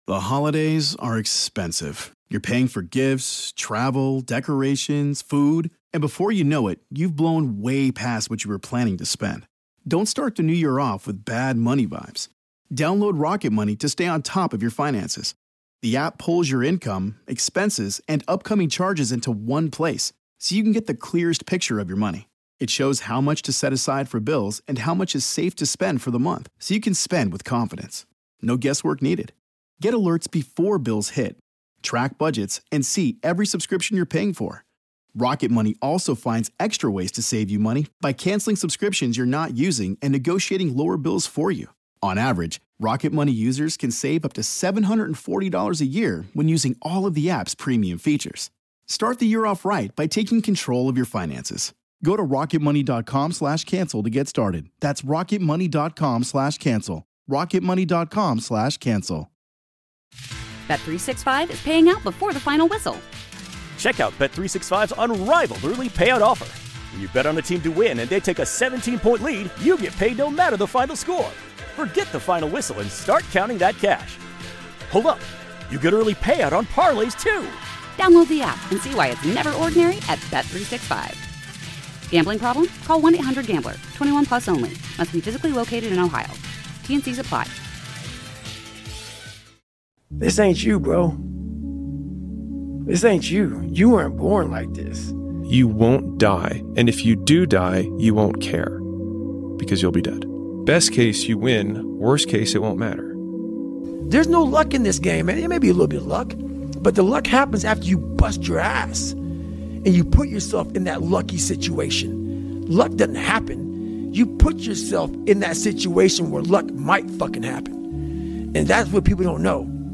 Hear powerful insights from Kevin Hart, David Goggins, Joe Rogan, Jocko Willink, Jon Jones, and more, as they discuss how to face your toughest opponent—you. Learn how to conquer mental barriers, push past your limits, and achieve greatness through sheer determination and discipline.